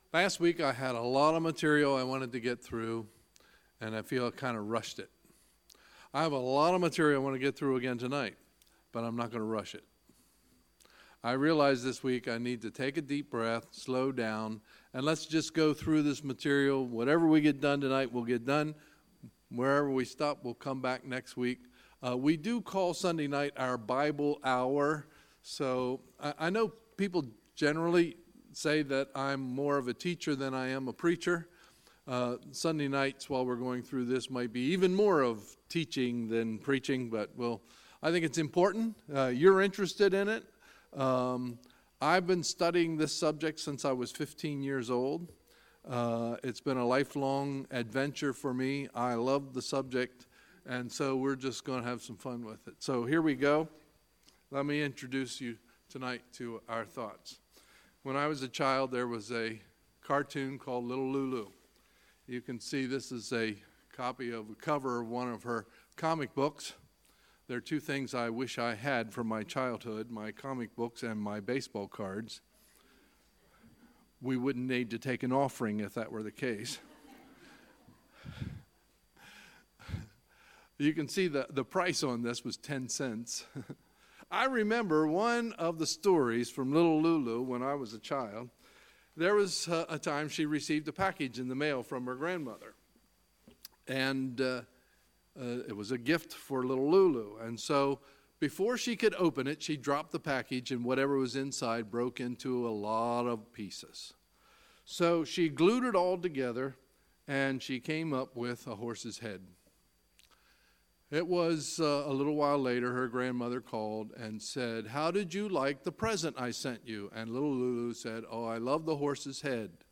Sunday, May 6, 2018 – Sunday Evening Service